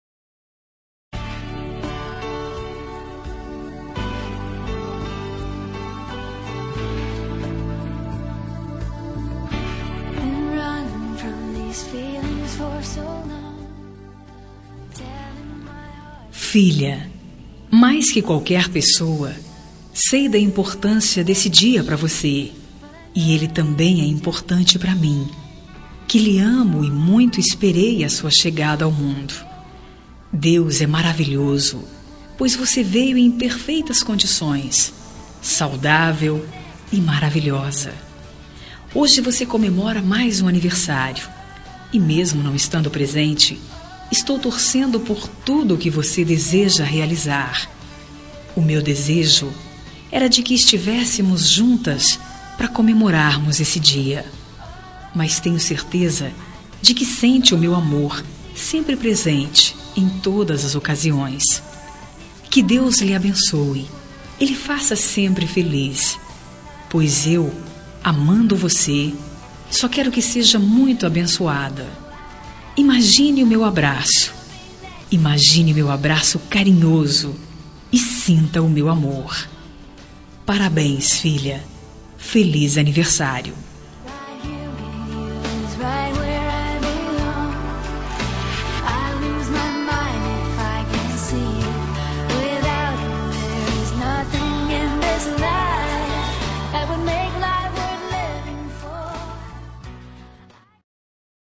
Telemensagem de Aniversário de Filha – Voz Feminina – Cód: 1773 – Distante